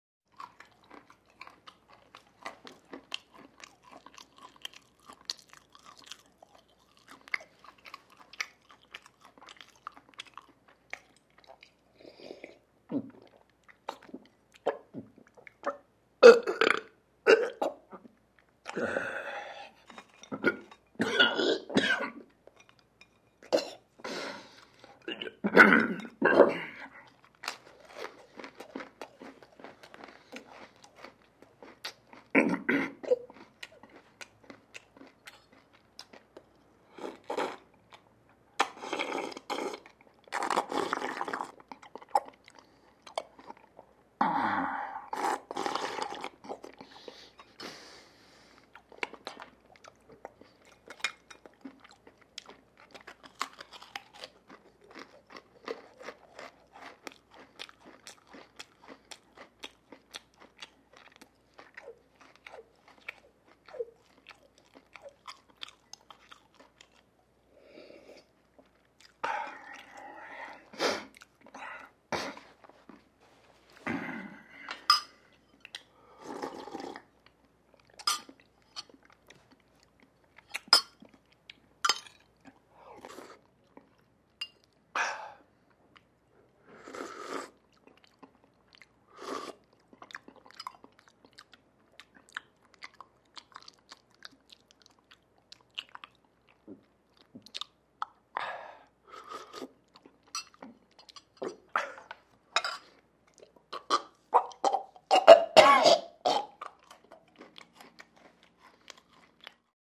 Звуки еды
Жевание чавканье сербанье отрыжка